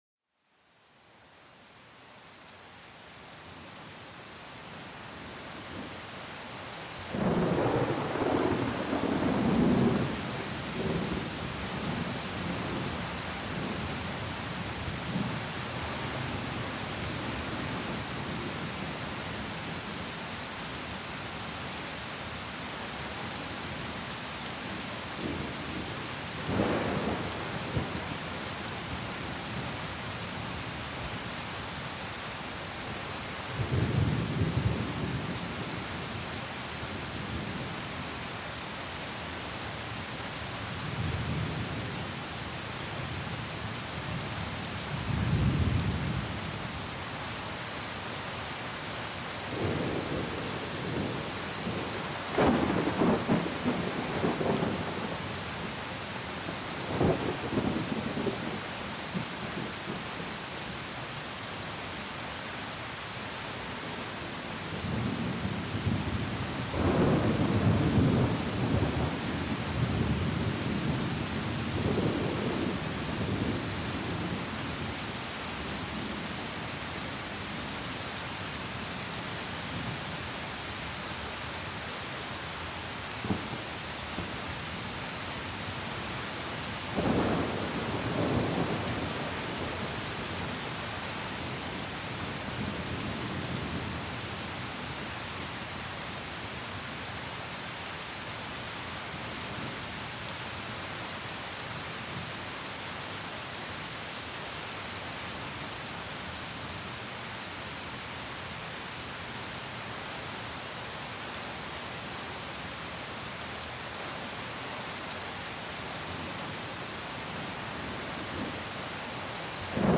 Pluie battante et grondements d'orage pour une relaxation profonde